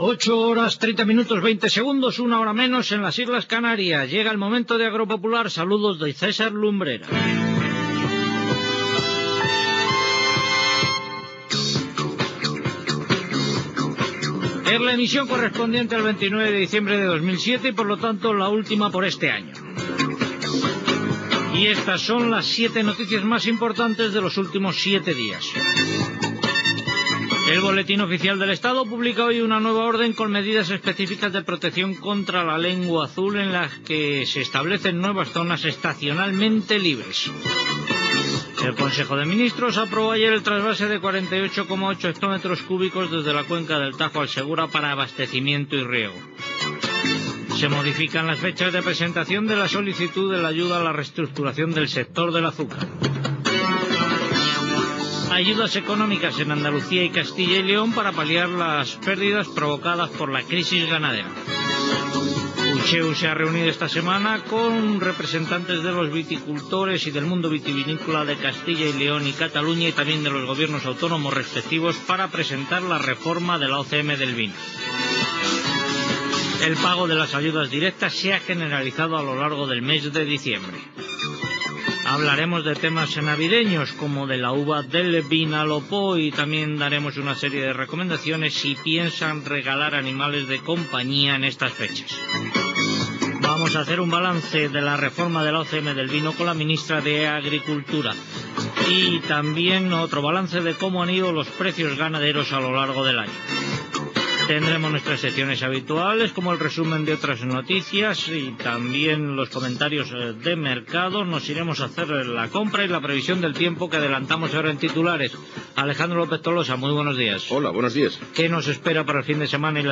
Hora, identificicació del programa, data, set notícies agrícoles i ramaderes, sumari del programa, informació meteorològica, equip i publicitat
Informatiu
FM